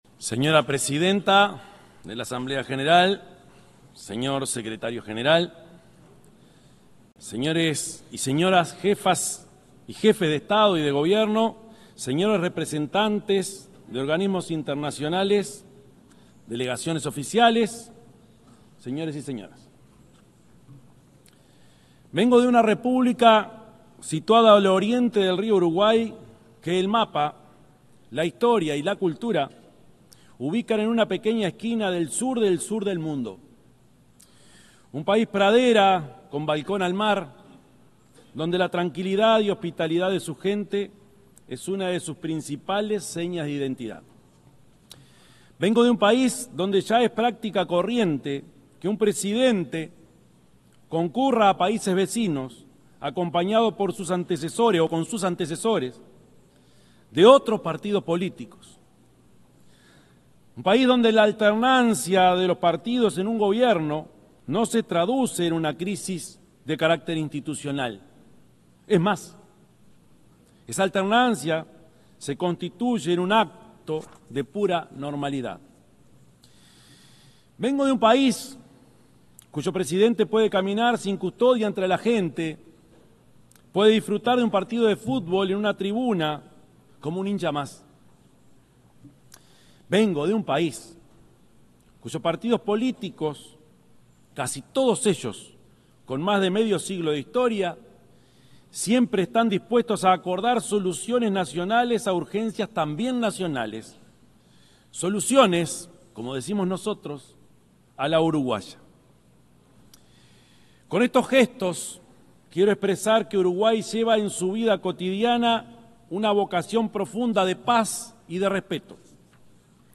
El presidente de la República, Yamandú Orsi, expuso este martes 23 en la Asamblea General de las Naciones Unidas, en la ciudad de Nueva York.